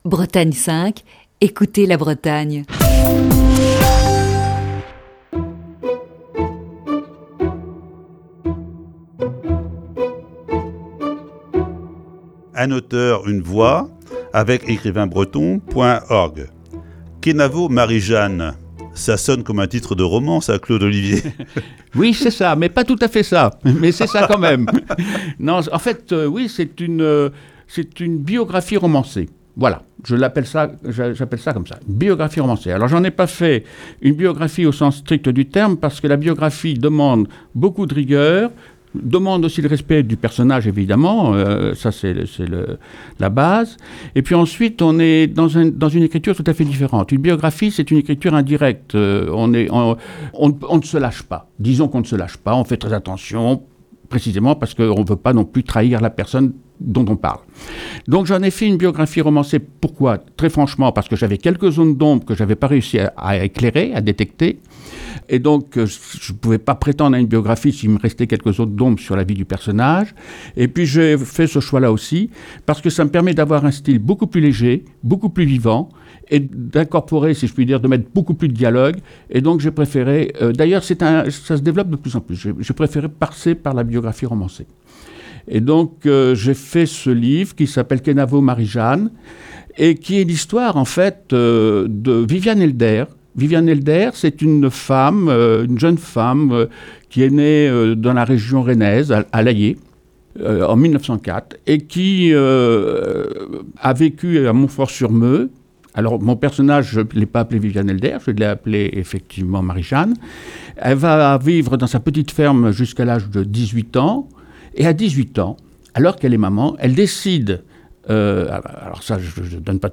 Voici ce mercredi, la troisième partie de cet entretien.